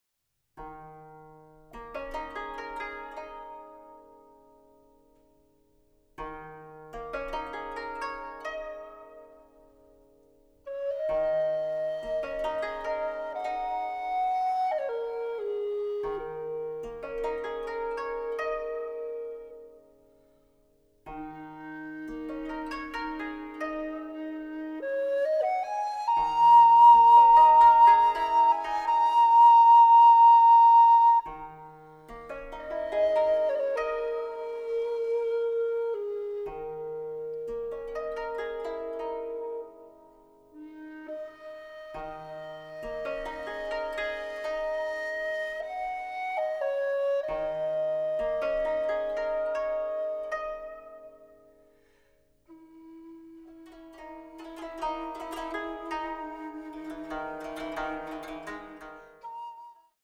Flöte